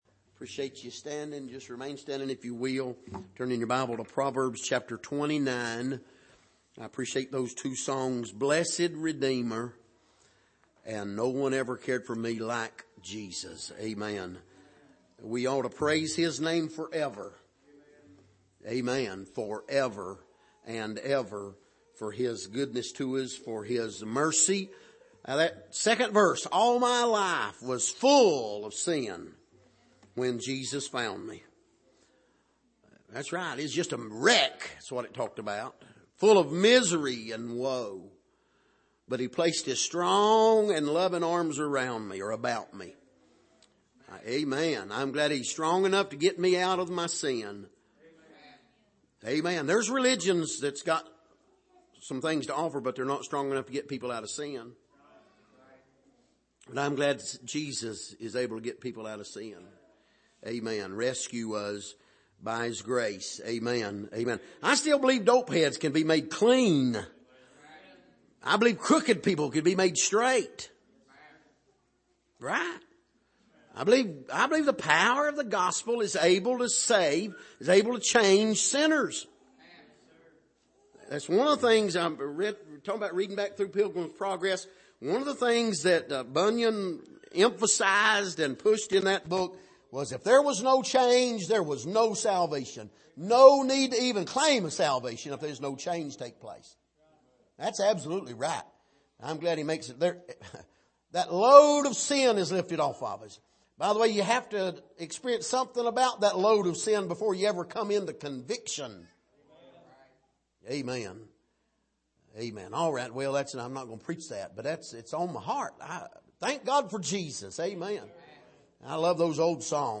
Passage: Proverbs 29:10-15 Service: Sunday Evening